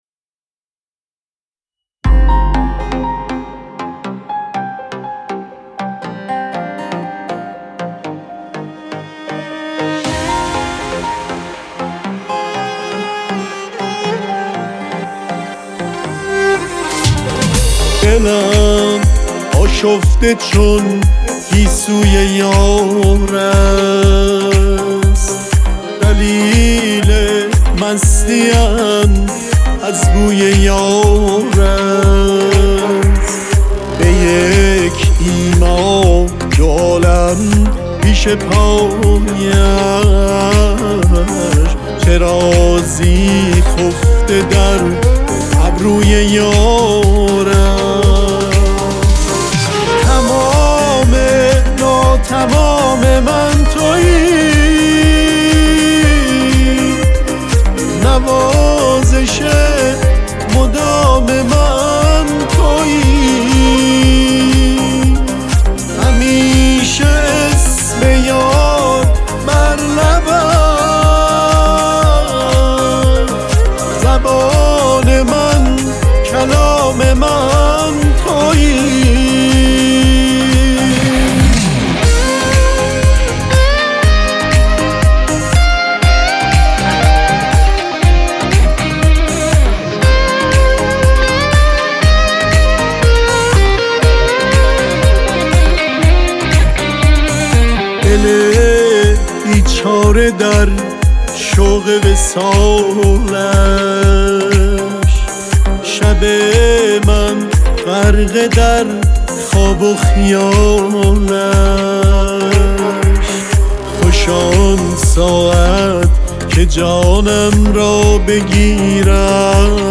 درود بر شما شما موزیک ویدیو هم میسازید یک تراک موزیک پاپ حدود ۳/۱۵دقیفه 1784